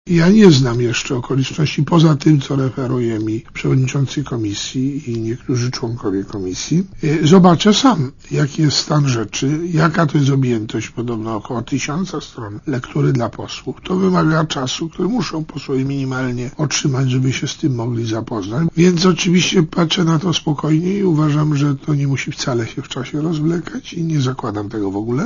Posłuchaj komentarza Józefa Oleksego